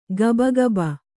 ♪ gabagaba